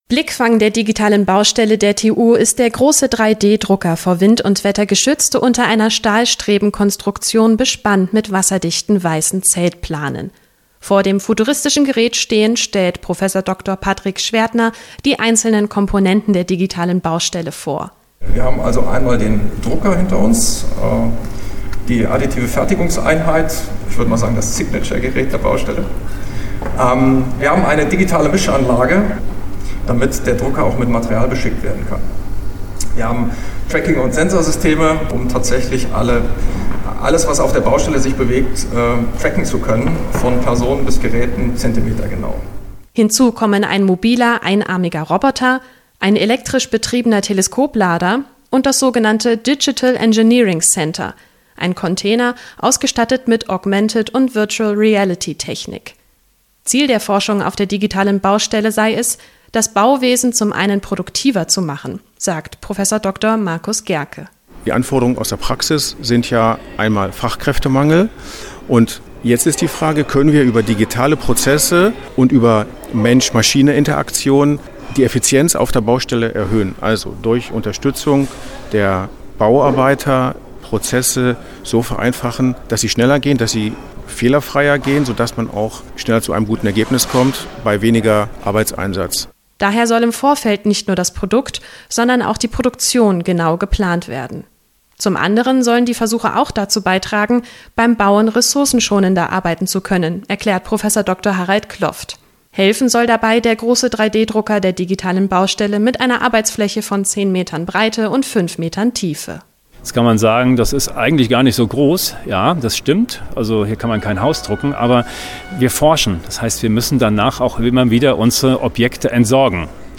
Von der Eröffnung der digitalen Baustelle am Campus Ost berichtet